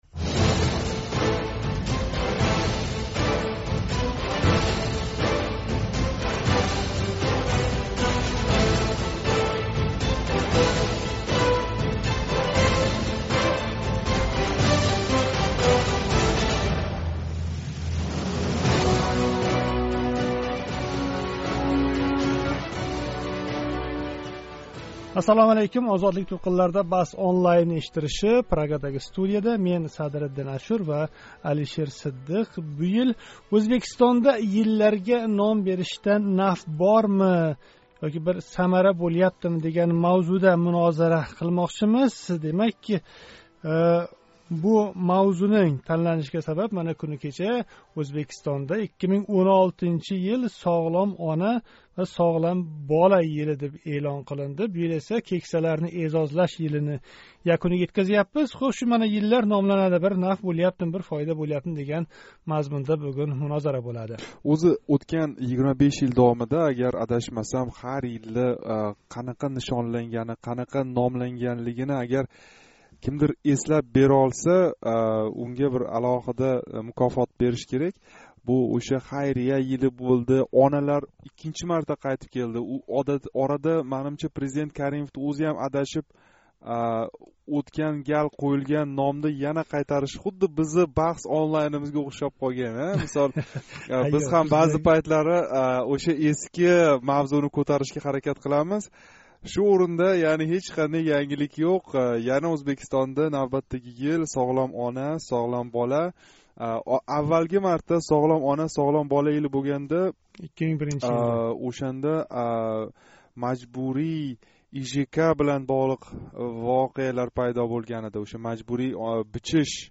BahsOnlineнинг бу галги сонида Ўзбекистонда йилларга қўйилаётган номлардан самара бор-йўқлиги мунозара қилинади. Бу номлар ортидан қабул қилинган ҳукумат дастурлари қанчалик бажариляпти? Агар сиз ҳам бу баҳсга қўшилмоқчи бўлсангиз бугун, 9 декабрь, чоршанба куни Тошкент вақти билан соат 19.05дан кейин OzodlikOnline, OzodSkype, OzodOvoz Скайп манзилларига боғланинг.